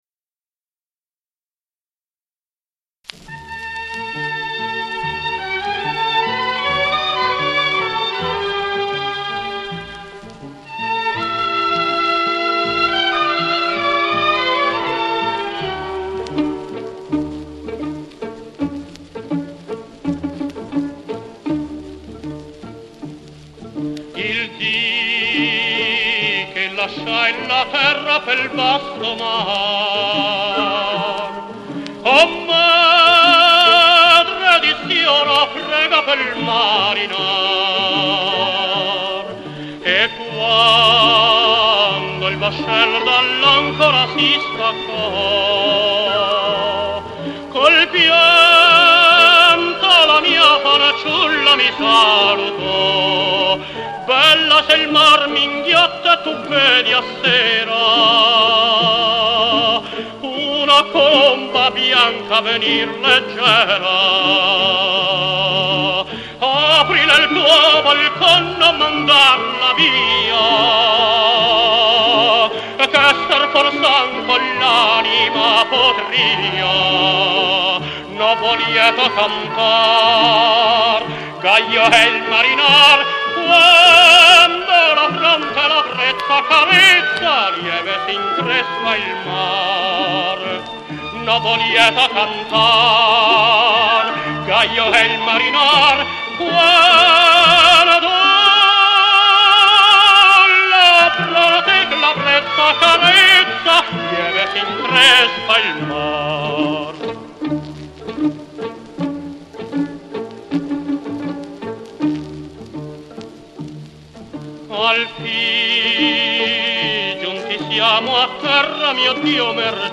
con accompagnamento d'orchestra